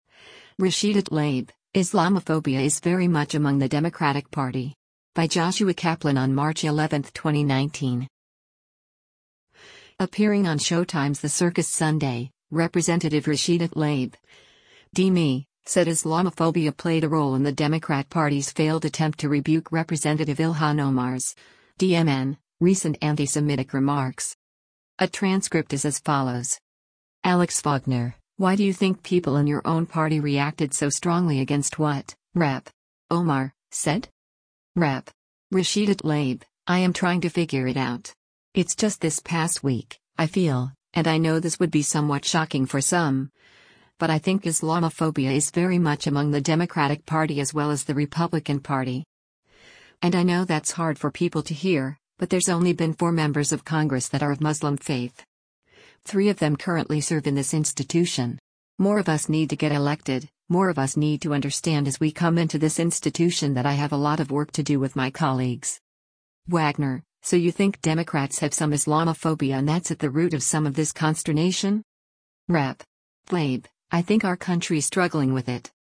Appearing on Showtime’s The Circus Sunday, Rep. Rashida Tlaib (D-MI) said Islamophobia played a role in the Democrat Party’s failed attempt to rebuke Rep. Ilhan Omar’s (D-MN) recent antisemitic remarks.